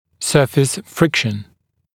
[‘sɜːfɪs ‘frɪkʃn][‘сё:фис ‘фрикшн]поверхностное трение